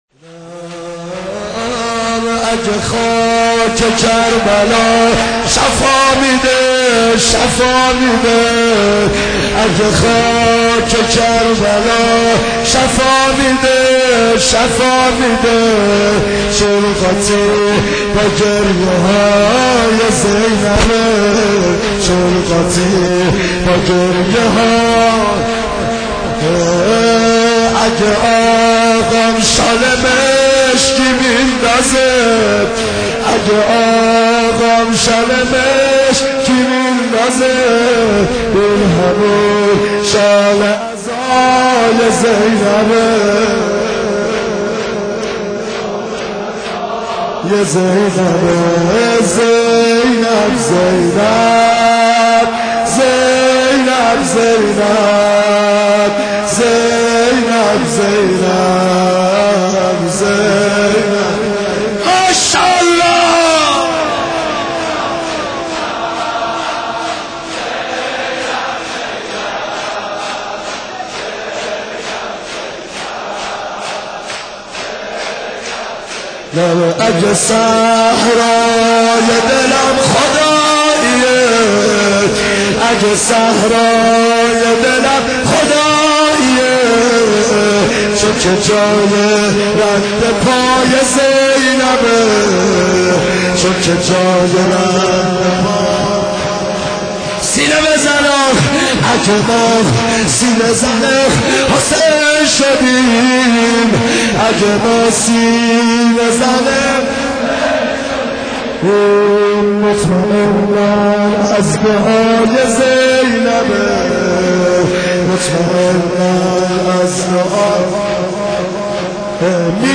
8 بهمن 95 - شور - اگر خاک کربلا شفا میده